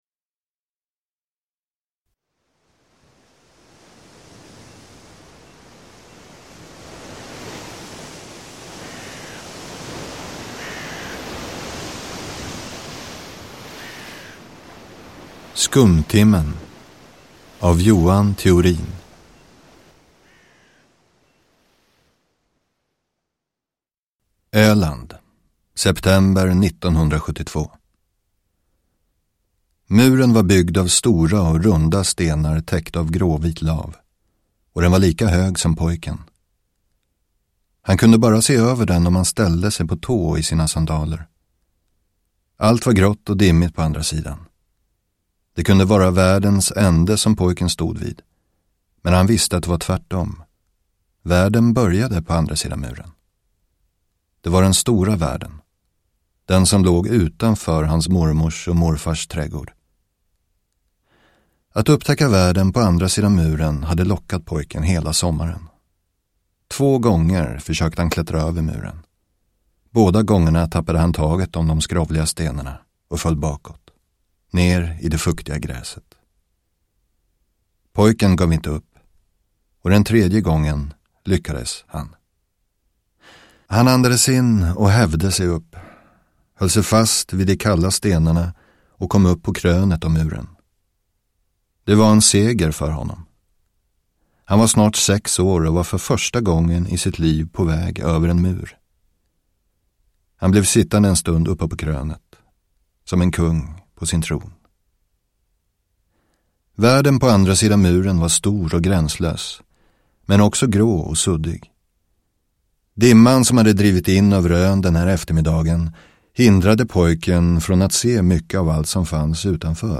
Nedladdningsbar Ljudbok
Berättare